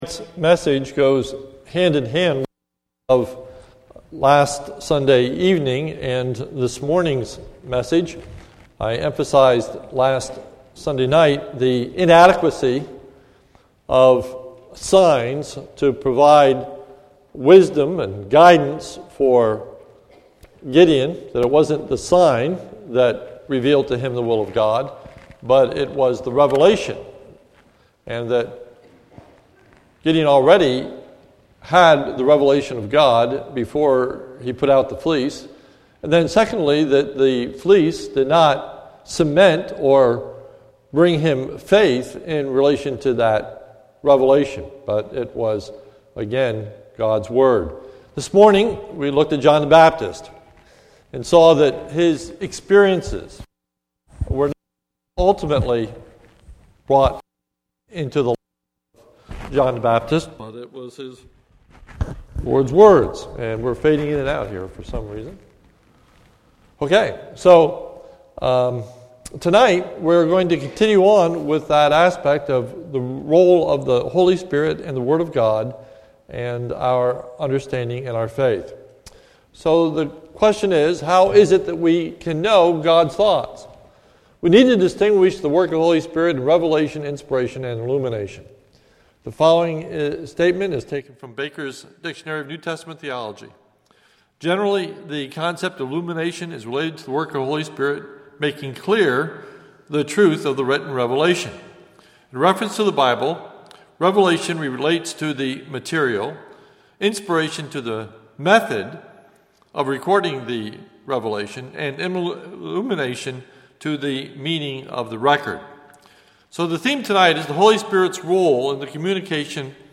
This is a sermon recorded at the Lebanon Bible Fellowship Church, in Lebanon, PA, on 10/26/2014 during the evening service